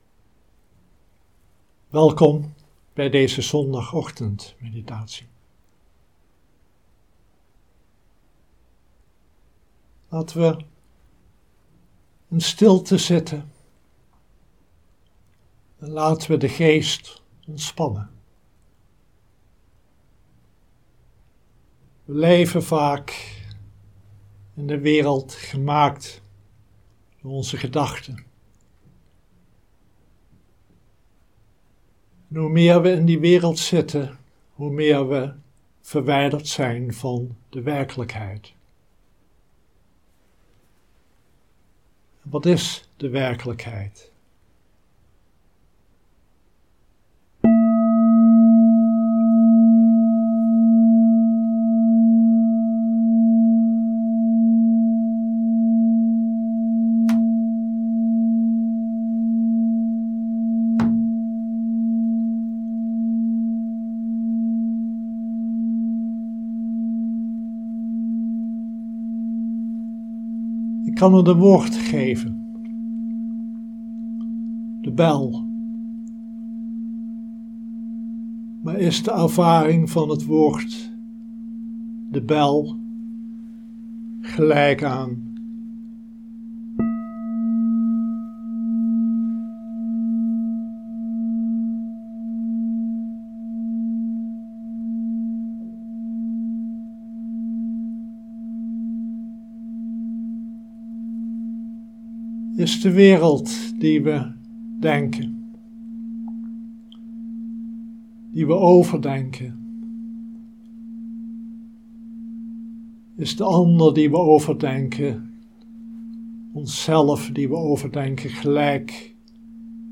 Dharma-onderwijs
Livestream opname